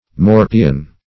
Search Result for " morpion" : The Collaborative International Dictionary of English v.0.48: Morpion \Mor"pi*on\, n. [F., fr. mordre to bite + L. pedis louse.]